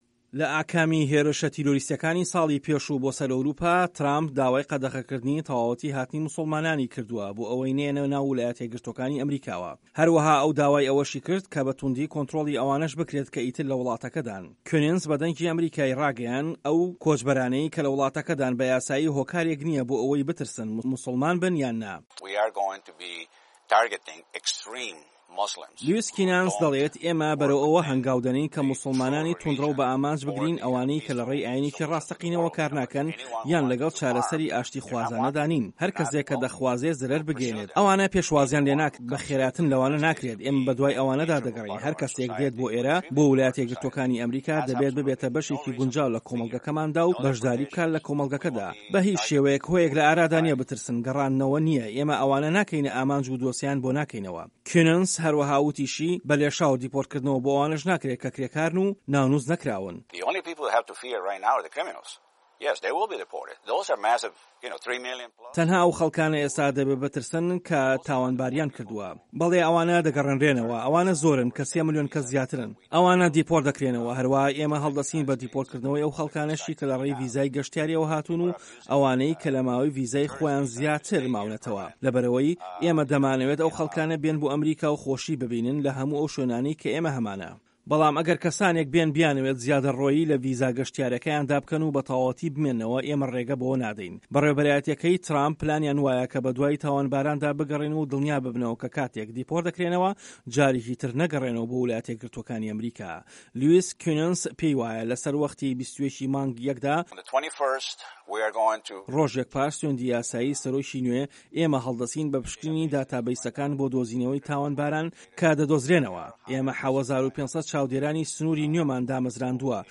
ڕاپۆرتی بەڕێوبەرایەتییەکەی ترامپ و دۆسێی کۆچبەران